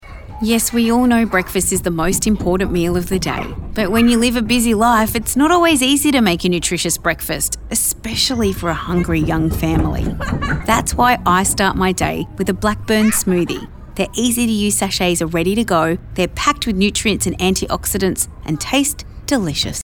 Commercial Voice Over Talent for Radio & TV Ads